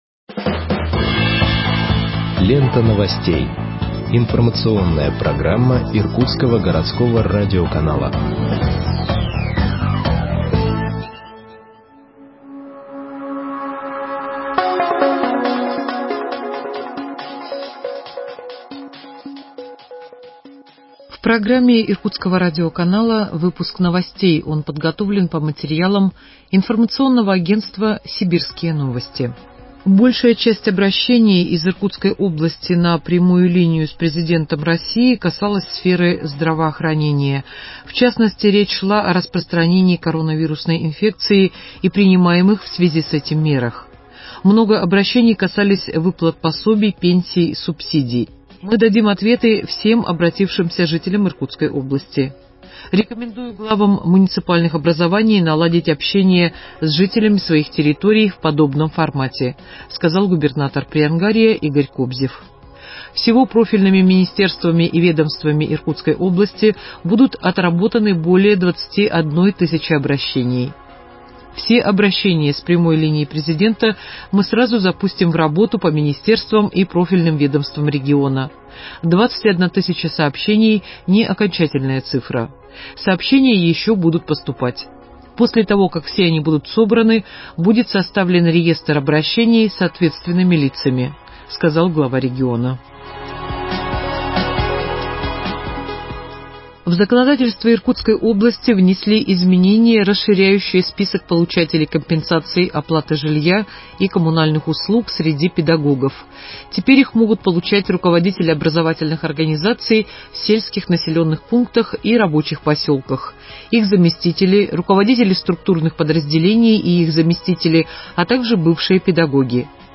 Выпуск новостей от 16.07.2021 № 2